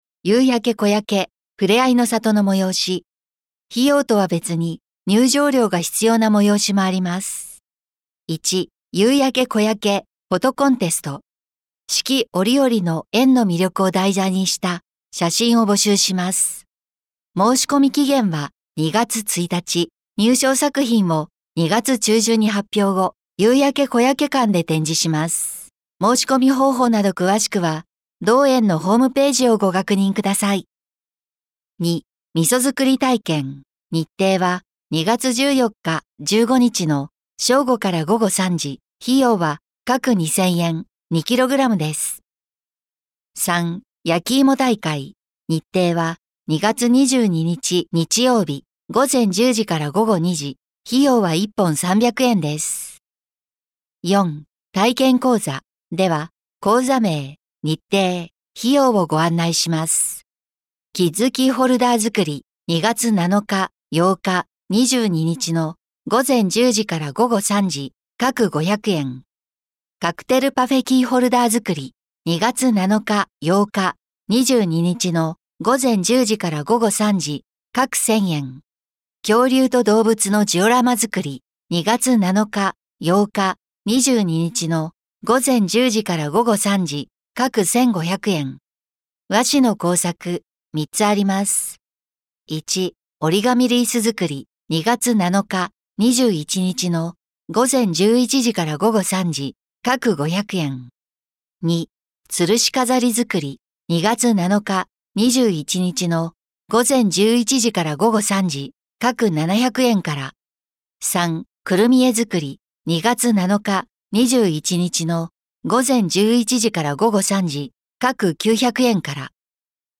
「声の広報」は、視覚障害がある方を対象に「広報はちおうじ」の記事を再編集し、音声にしたものです。